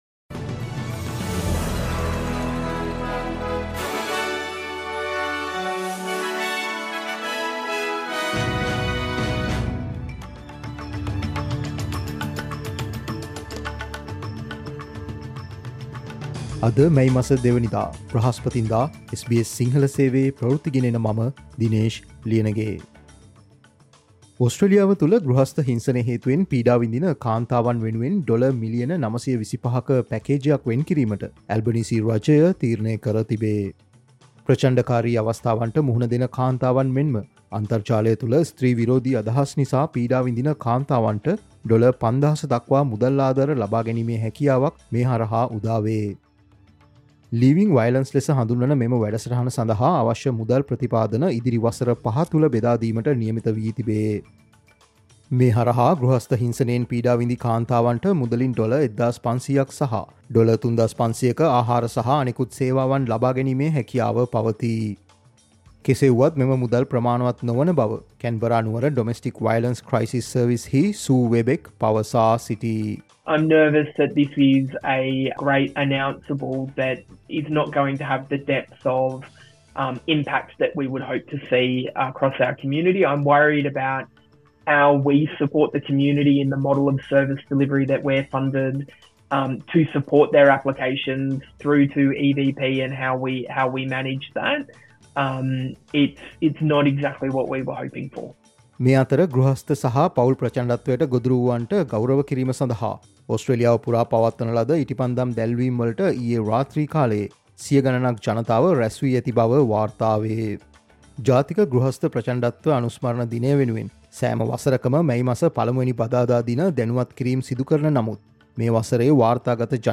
Australian news in Sinhala, foreign and sports news in brief - listen, Sinhala Radio News Flash on Thursday 02 of May 2024